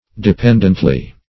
dependently - definition of dependently - synonyms, pronunciation, spelling from Free Dictionary Search Result for " dependently" : The Collaborative International Dictionary of English v.0.48: Dependently \De*pend"ent*ly\, adv. In a dependent manner.